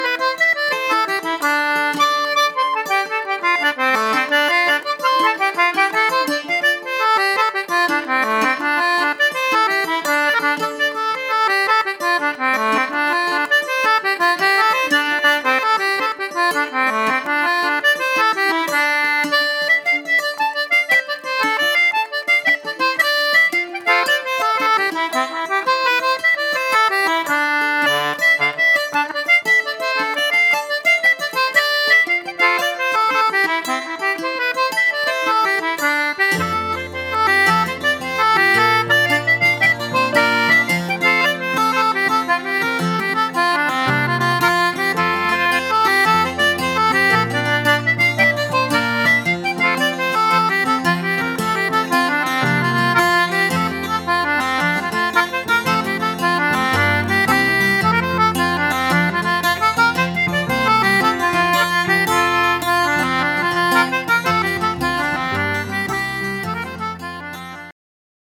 Fiddle and concertina from County Meath.